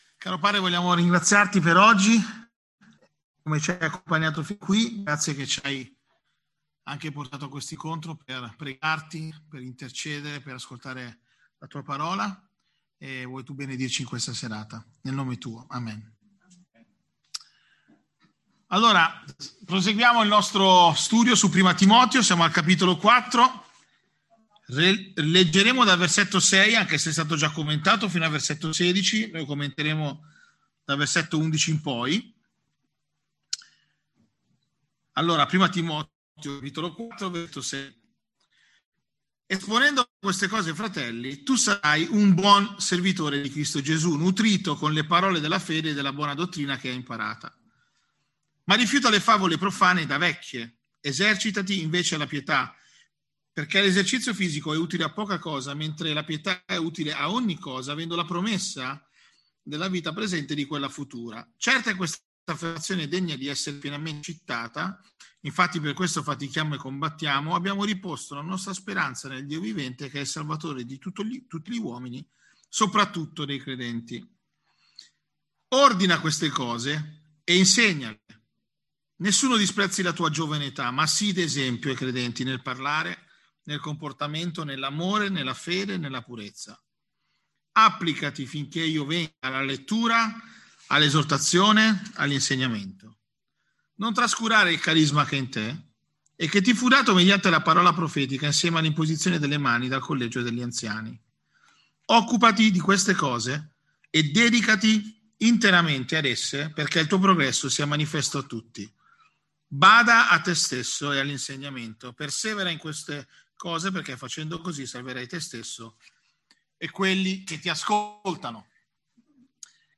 Tutti i sermoni